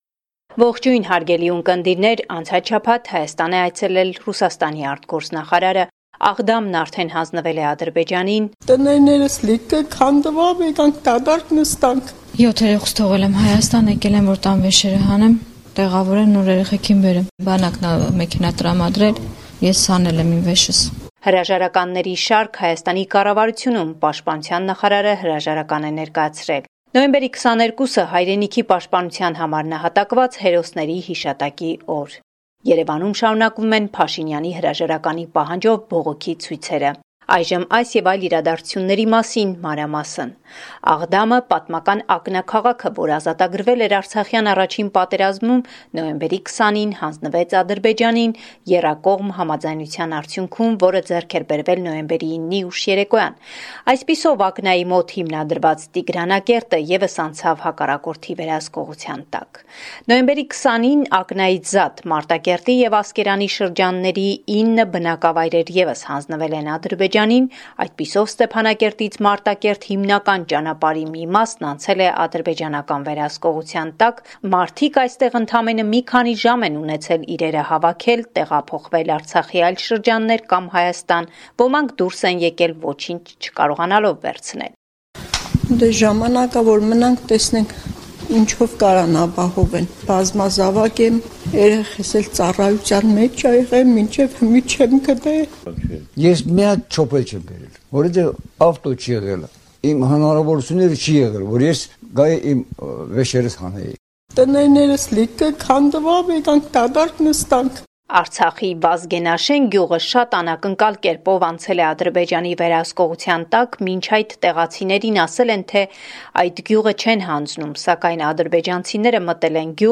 Latest News from Armenia – 24 November 2020